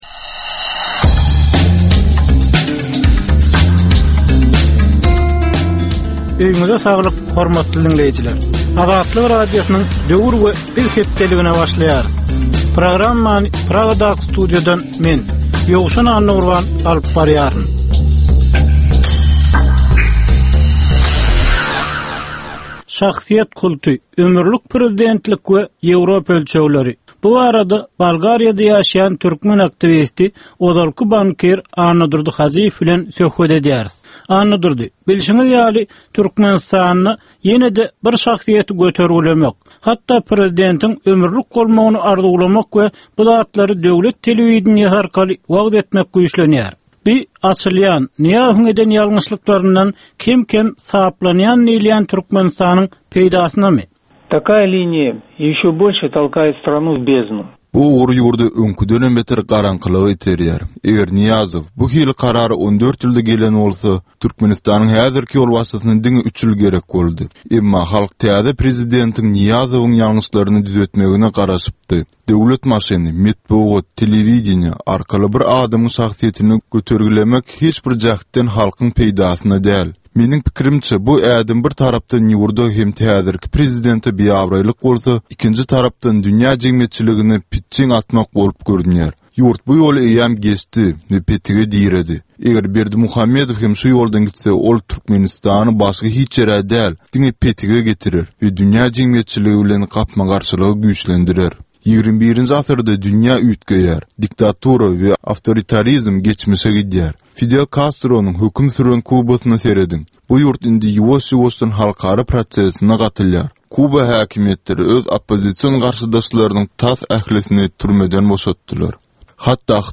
Türkmen jemgyýetindäki döwrüň meseleleri we döwrüň anyk bir meselesi barada ýörite gepleşik. Bu gepleşikde diňleýjiler, synçylar we bilermenler döwrüň anyk bir meselesi barada pikir öwürýärler, öz garaýyşlaryny we tekliplerini orta atýarlar.